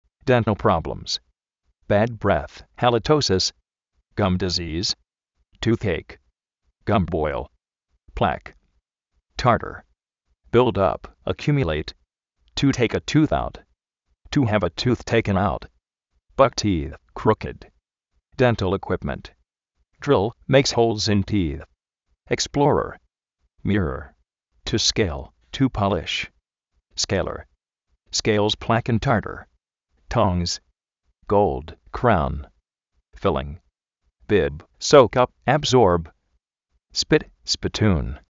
déntal próblems
bad brédz, jalitóusis
tuzéik
déntal ikuípment